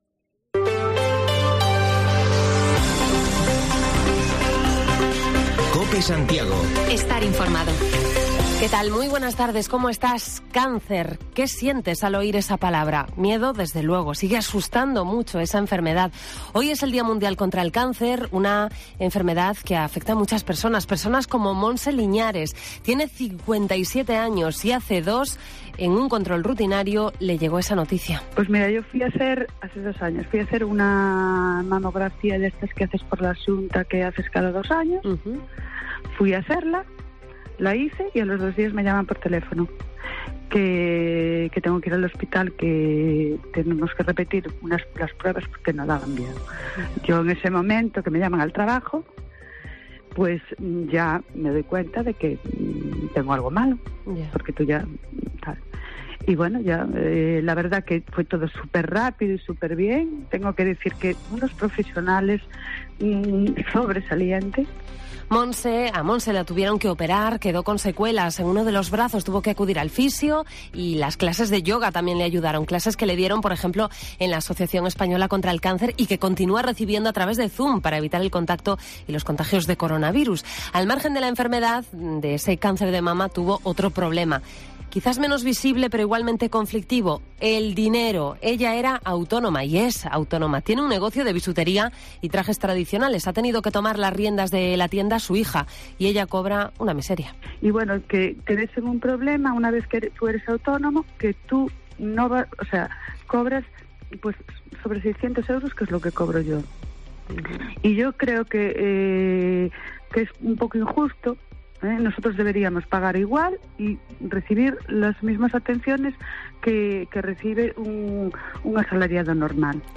Resumen de lo más destacado hasta esta hora en Santiago y Barbanza: hablamos de la incidencia del cáncer en nuestra área sanitaria con testimonios de pacientes y expertos y también recogimos las quejas de usuarios de entidades financieras, cada vez con más servicios automatizados y que suponen dificultades sobre todo para las personas mayores.